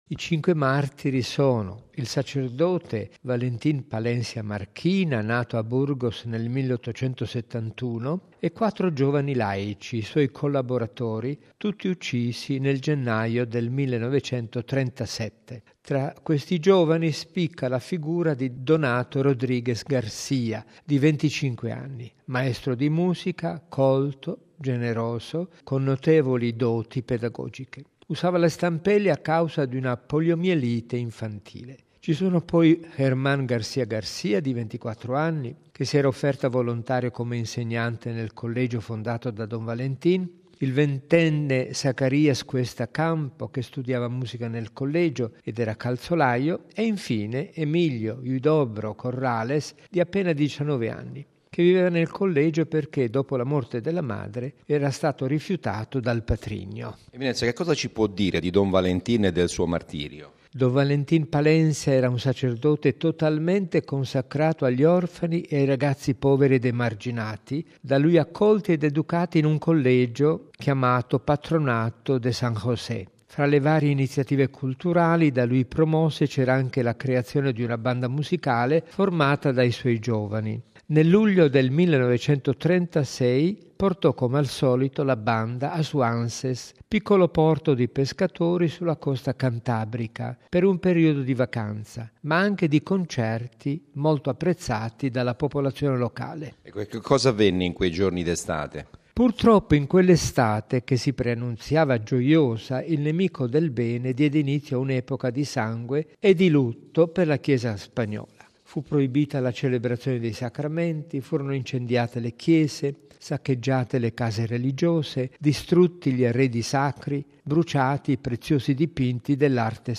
Sono stati beatificati oggi a Burgos, in Spagna, il sacerdote Valentín Palencia Marquina e quattro compagni martiri, uccisi in odio alla fede nel 1936 durante la guerra civile spagnola. Al rito era presente anche il cardinale Angelo Amato, prefetto della Congregazione delle Cause dei Santi.